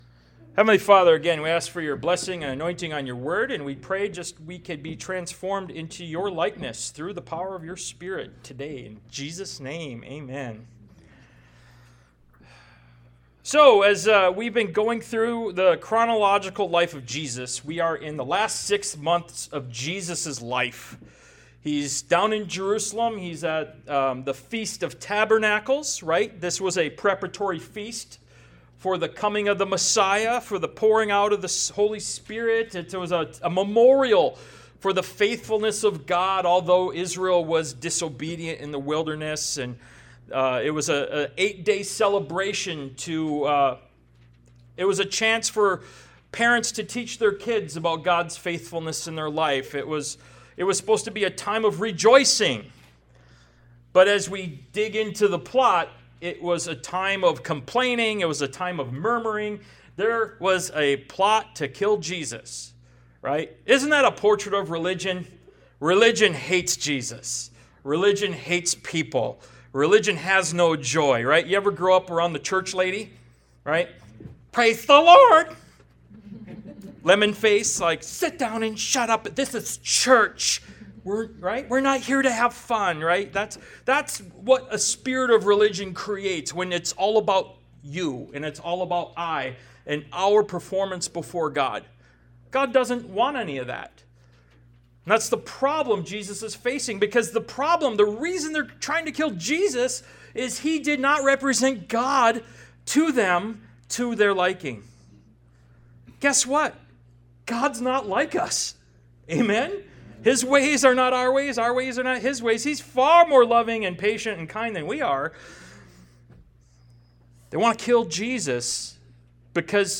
Ministry of Jesus Service Type: Sunday Morning « “A Time of Rejoicing” Ministry of Jesus Part 51 Ephesians 1:18-23 »